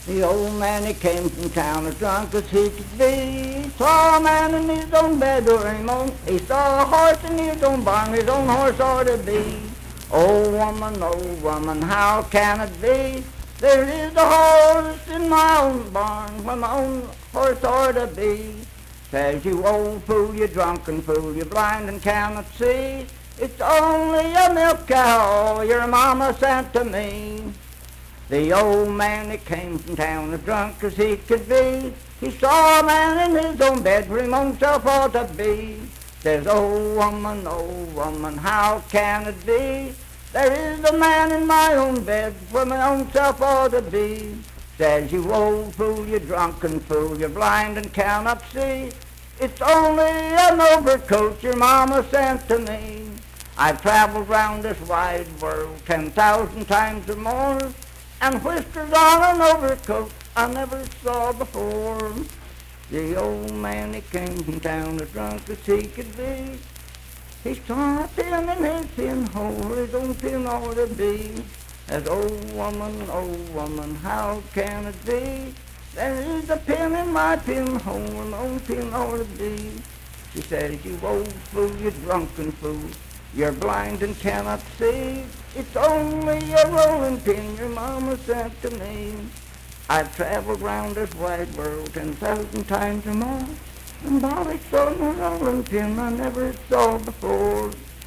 Unaccompanied vocal music performance
Marriage and Marital Relations, Humor and Nonsense, Bawdy Songs
Voice (sung)